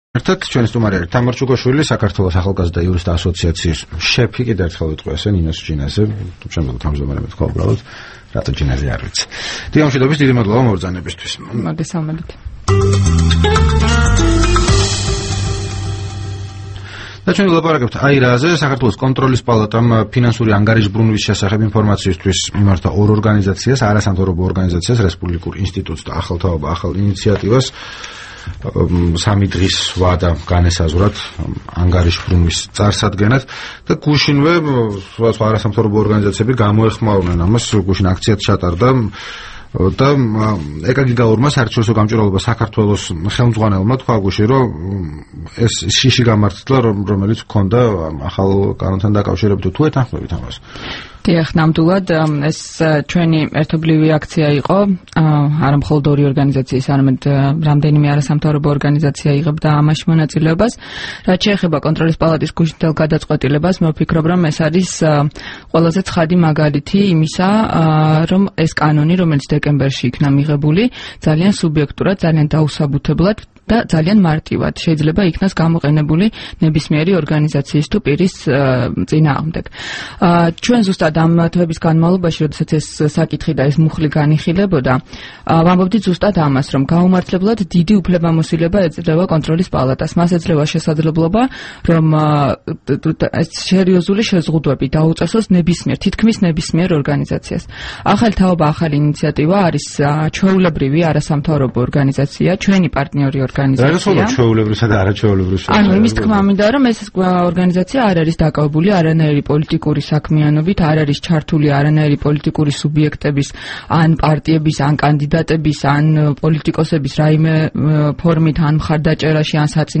რადიო თავისუფლების თბილისის სტუდიაში